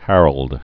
(hărăld) also Har·old III (hărəld) Known as "Harald (or Harold) Hardrada." 1015-1066.